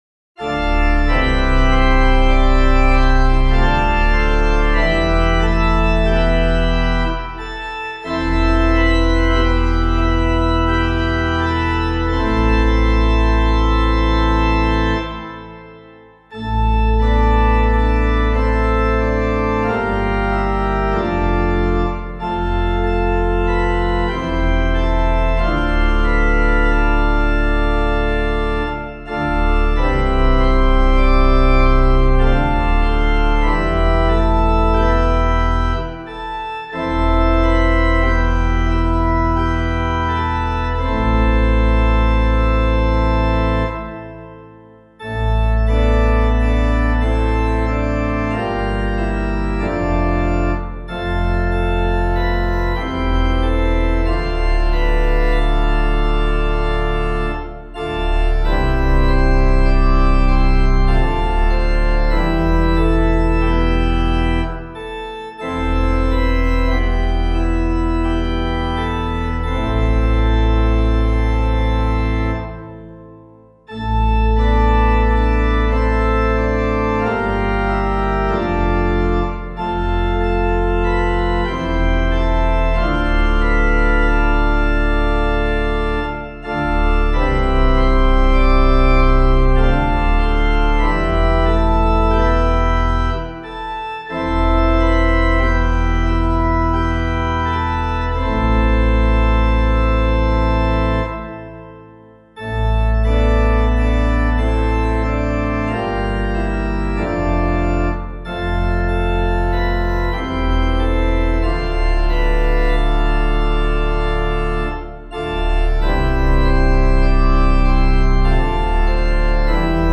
Composer:    Chant, mode I.
organpiano